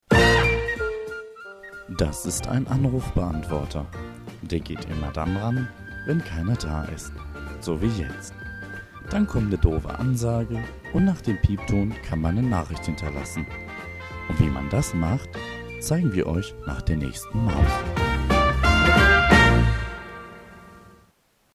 deutscher Sprecher
Sprechprobe: Industrie (Muttersprache):
german voice over artist